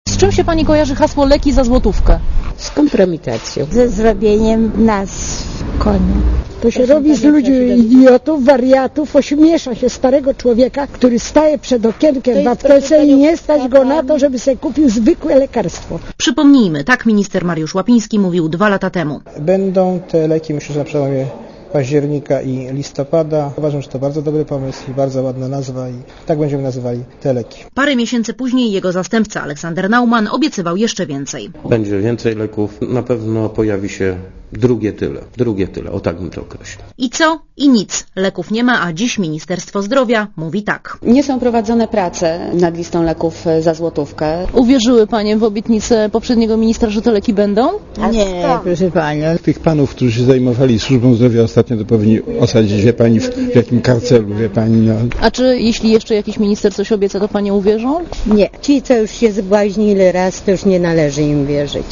Źródło: PAP Relacja reportera Radia Zet Leki za złotówkę wymyślił i wielokrotnie obiecywał były minister Mariusz Łapiński .